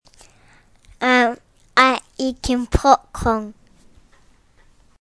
Eating Popcorn
364-eating-popcorn.mp3